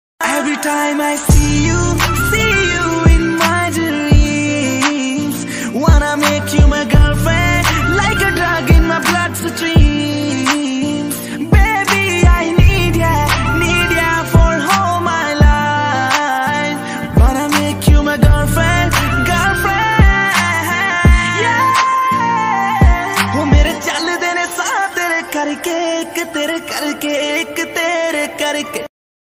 New punjabi song ringtone